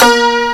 PIZTWANG.WAV